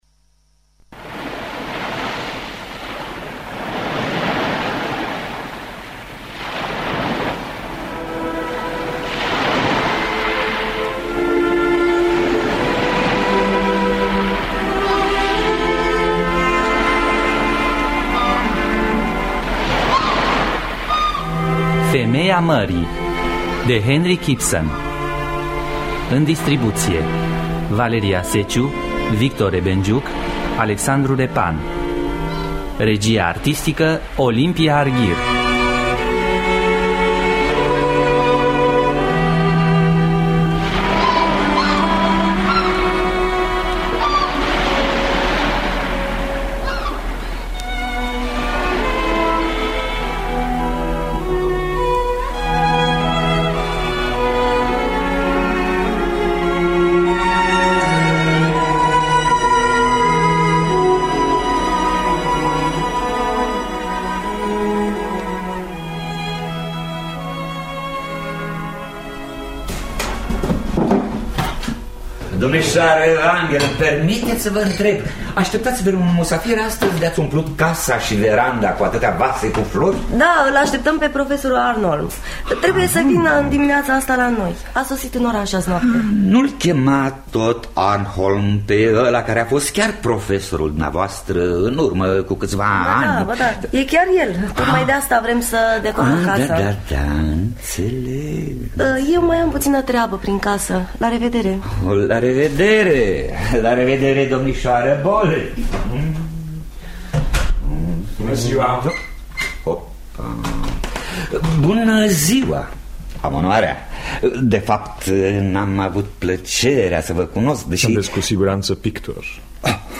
Femeia mării de Henrik Ibsen – Teatru Radiofonic Online